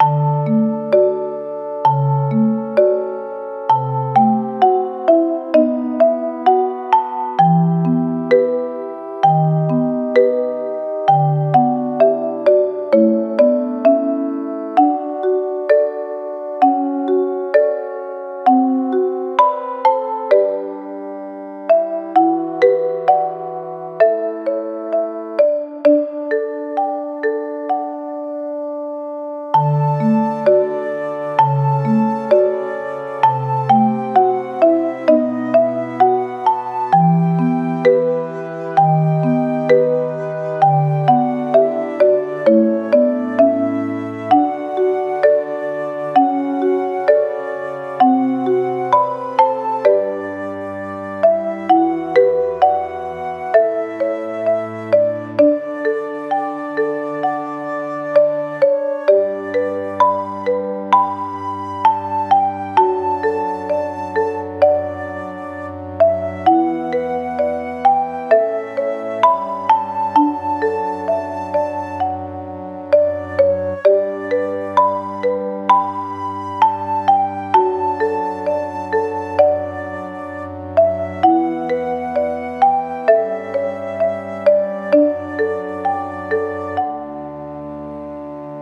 ゆったりした楽曲
【イメージ】やさしい・和解 など